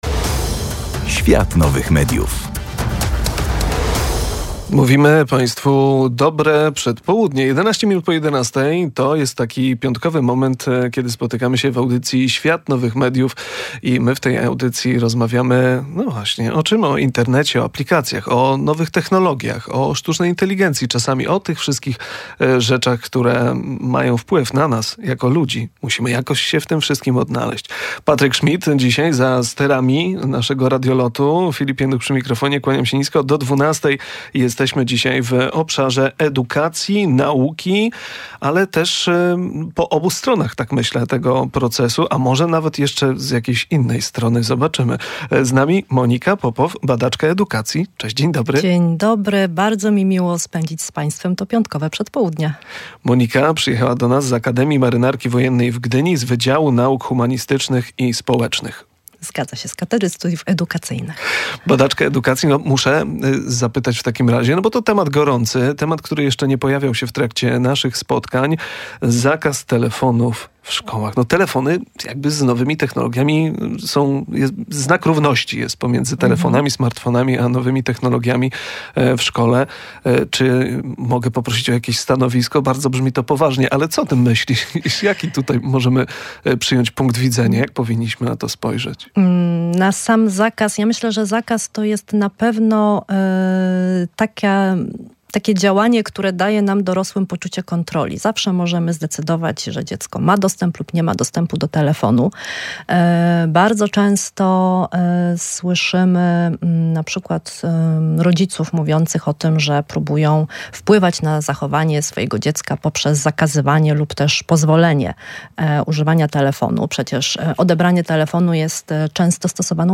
W audycji Świat Nowych Mediów spotykamy się, by porozmawiać o edukacji i jej wielu punktach stycznych z nowymi technologiami.